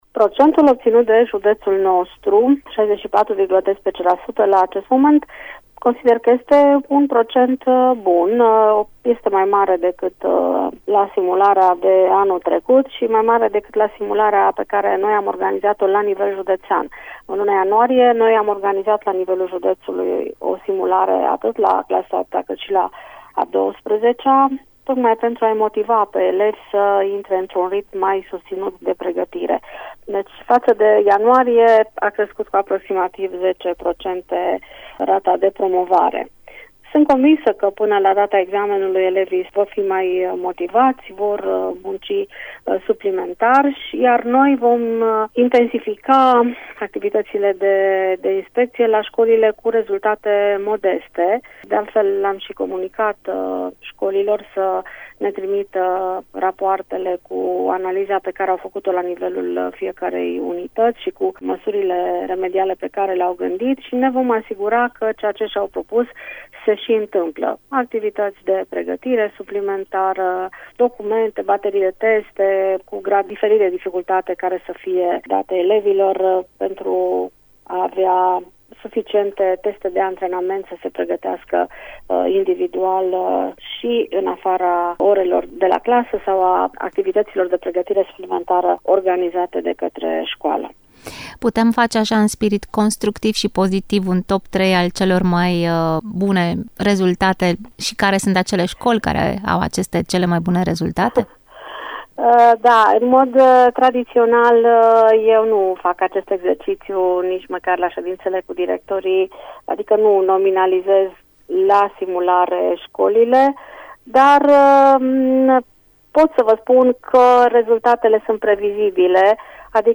Șefa Inspectoratului Școlar Județean Cluj, Marinela Marc, spune că rezultatele sunt bune și ne spune și ce măsuri au fost luate deja în școlile unde rezultatele obținute au fost mai modeste.